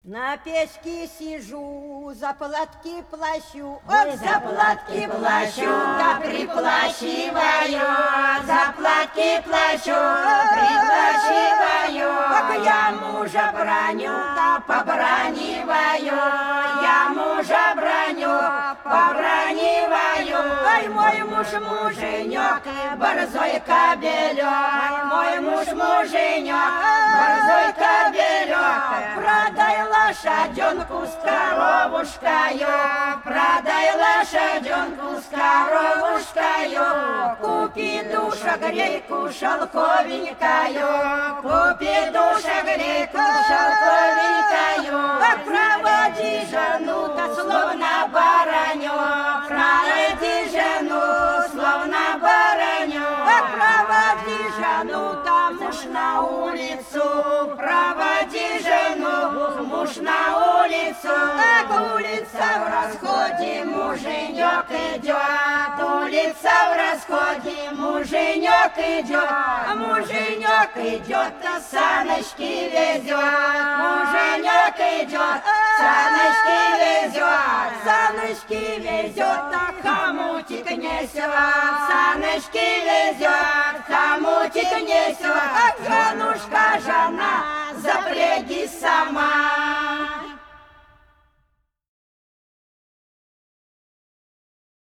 Пролетели все наши года На печке сижу – шуточная, плясовая (Фольклорный ансамбль села Пчелиновка Воронежской области)
24_На_печке_сижу_–_шуточная,_плясовая.mp3